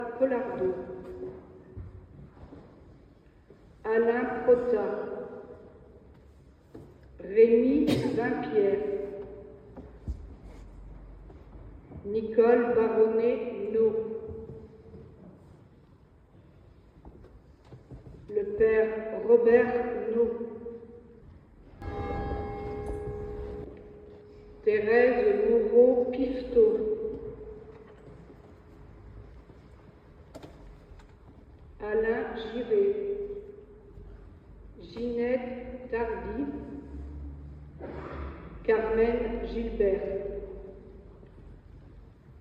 Le samedi 08/11/2025 18H30, une messe pour les défunts était célébrée dans l'église BRIOUX SUR BOUTONNE (79)